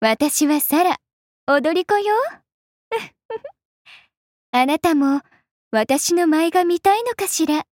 莎拉自我介绍语音.mp3